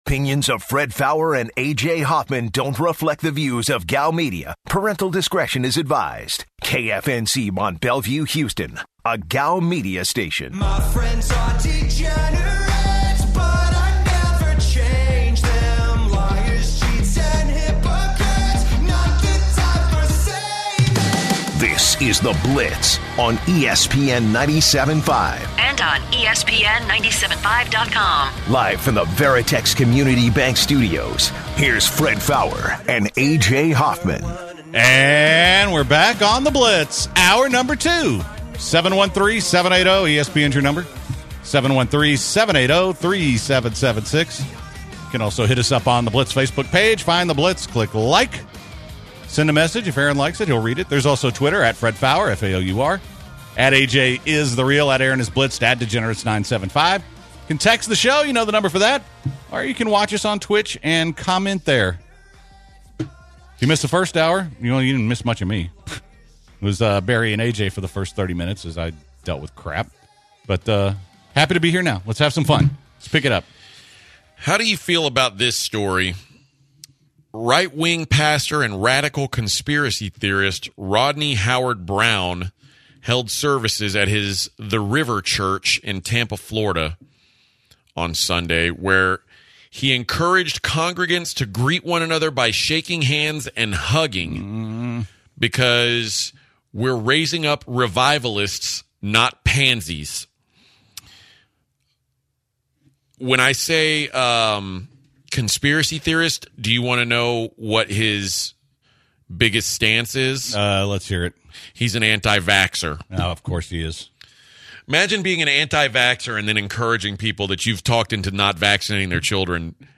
Corona virus and the epidemic kicks off the hour. The guys discuss a national shoot your shot day.